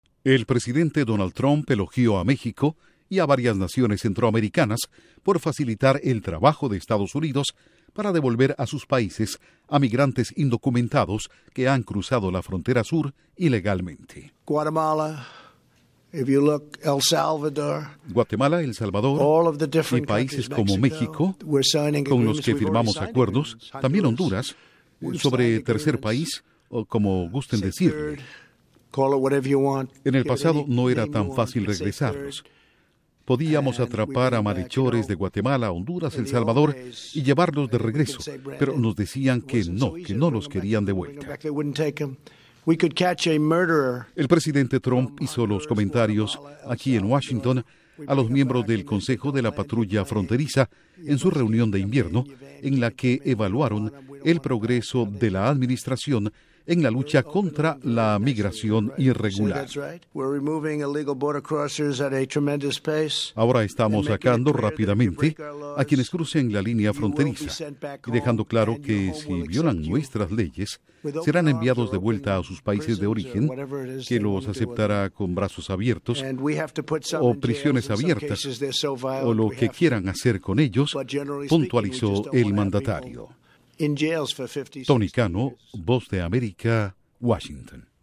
Duración: 1:28 Con declaraciones de Trump